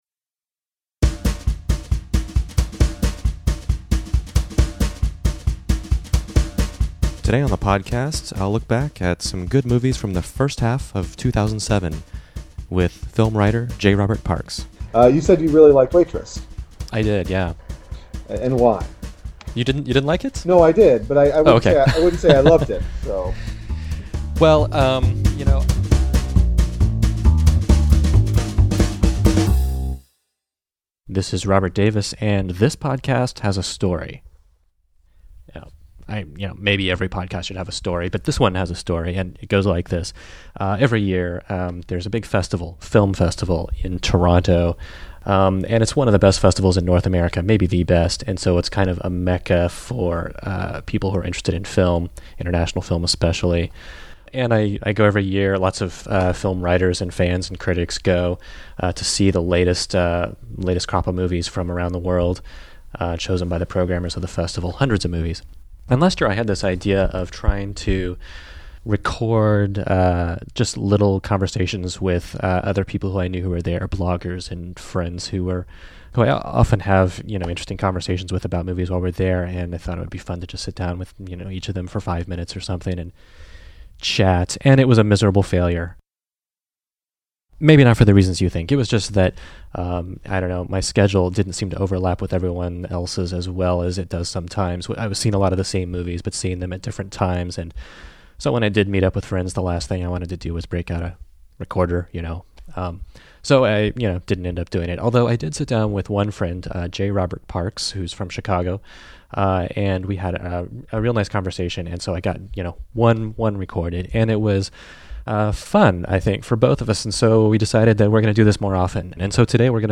However, I've been so quiet on the blog this year that I thought it would be a good idea to fill the second episode of the Errata podcast with a recap of some faves of the year as we pass the mid-point. It's not a top ten in the making, just a few good films that came up in conversation.